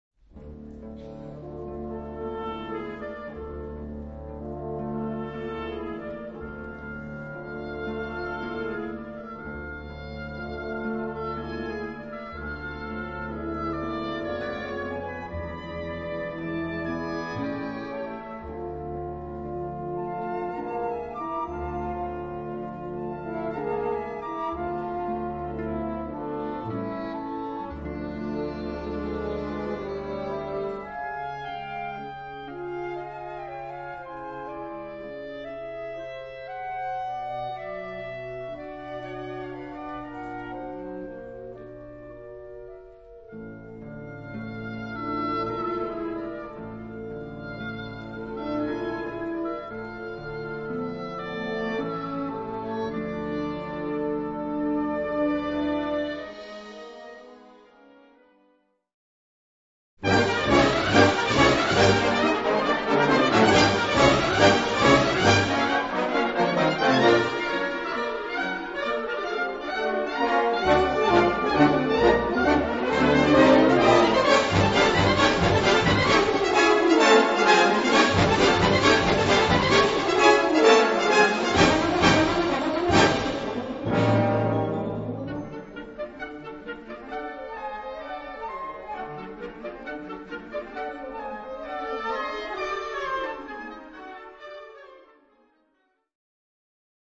Kategorie Blasorchester/HaFaBra